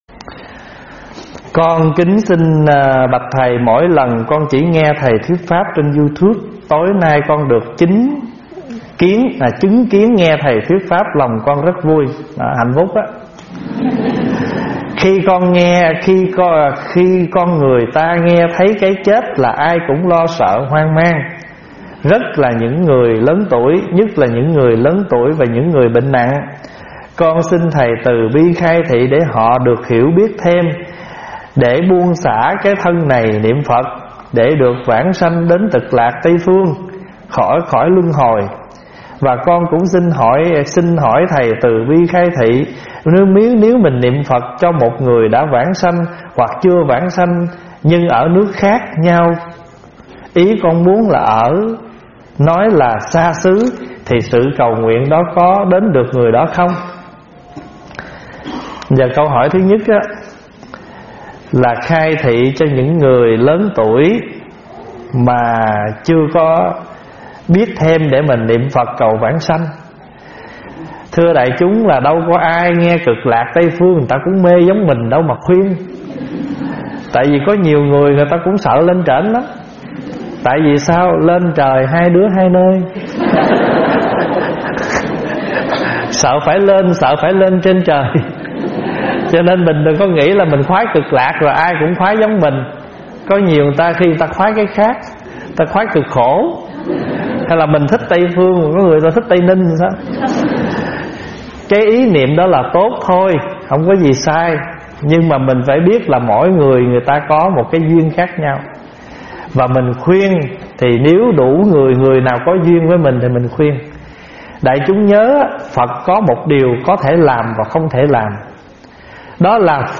Mp3 vấn đáp Khai Thị - ĐĐ. Thích Pháp Hòa
Mời quý phật tử nghe mp3 vấn đáp Khai Thị - ĐĐ. Thích Pháp Hòa giảng
Mp3 Thuyết Pháp     Thuyết Pháp Thích Pháp Hòa     Vấn đáp Phật Pháp